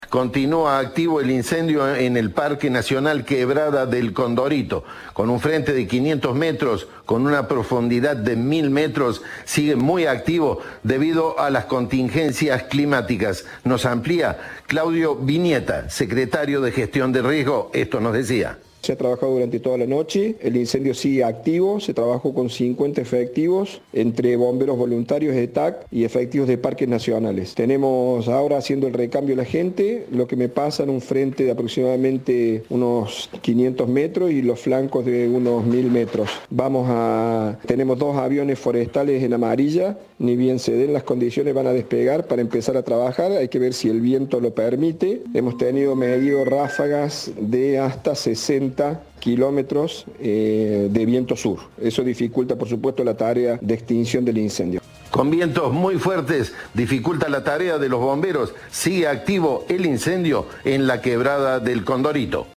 El secretario de Gestión de Riesgos y Cambio Climático, Claudio Vignetta, dijo más temprano a Cadena 3 que un equipo especial estuvo trabajando toda la noche en el combate del fuego.
Informe
Entrevista